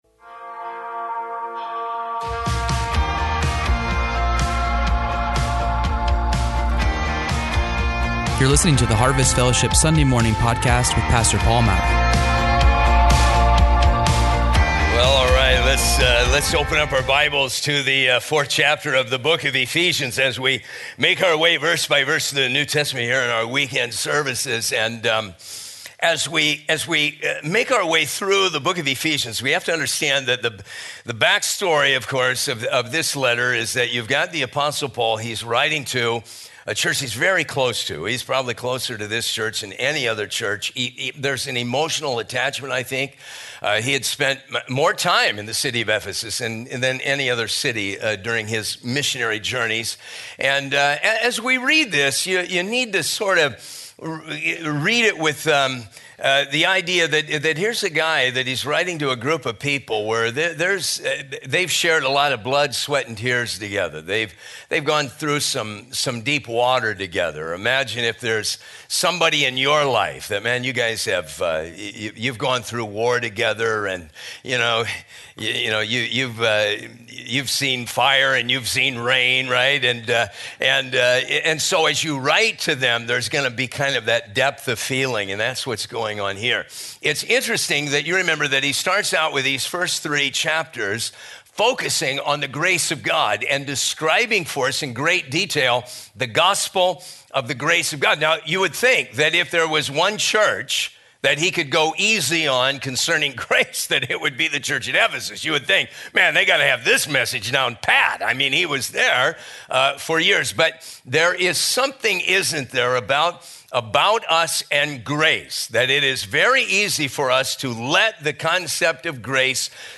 We feature verse by verse teachings through the Bible, topical messages, and updates from the staff and lead team.